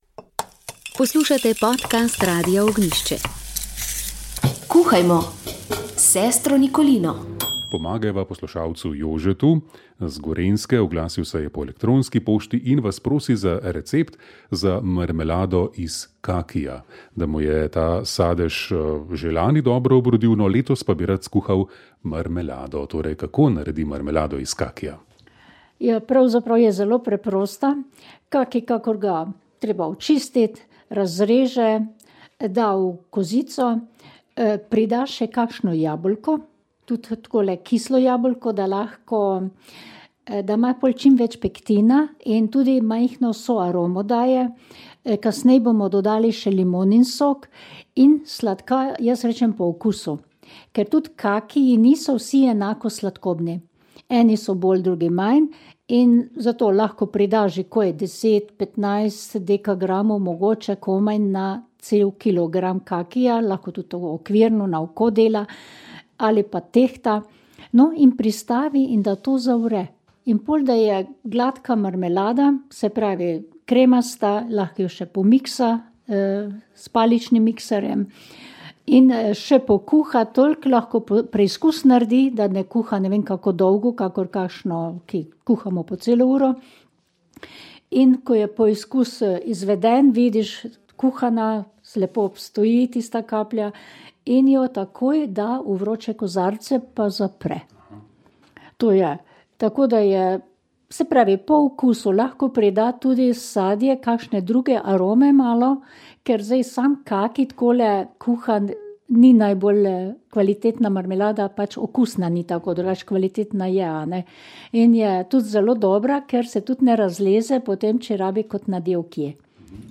komentar konklave mediji šovbiznis